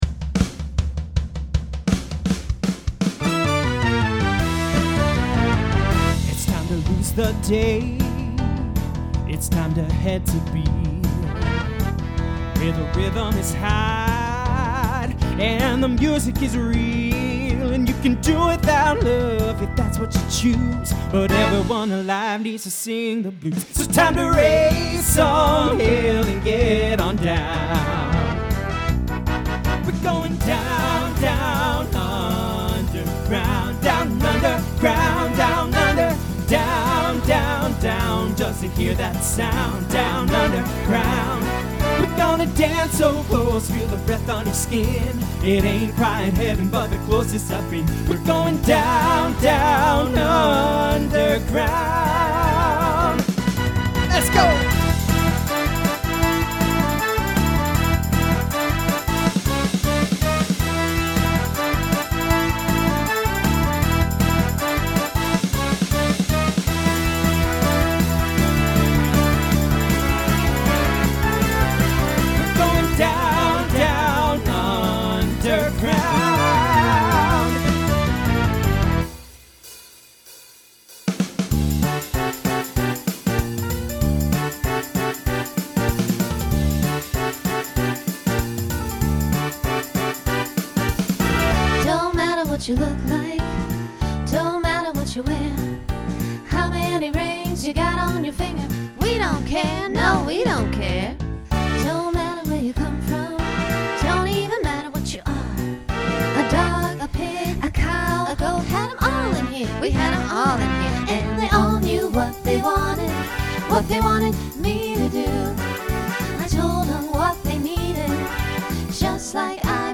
Genre Broadway/Film , Rock Instrumental combo
Voicing Mixed